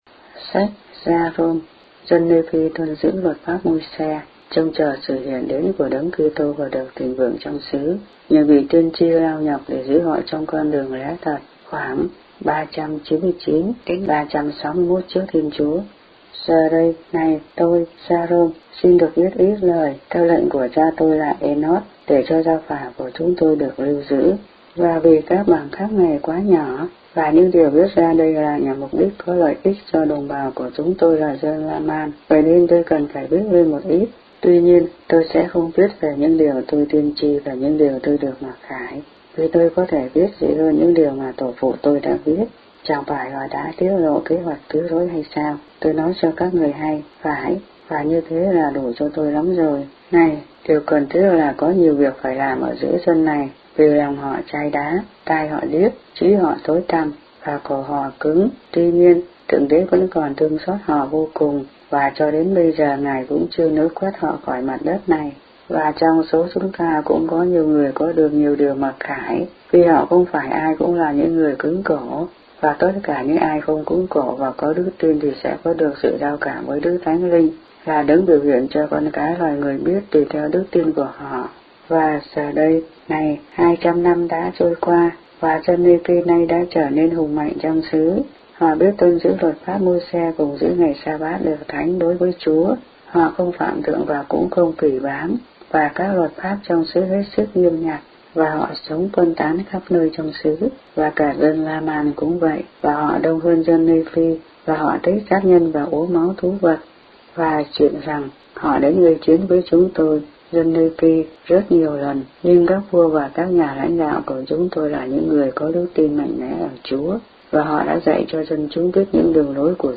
The Book of Mormon read aloud in Vietnamese.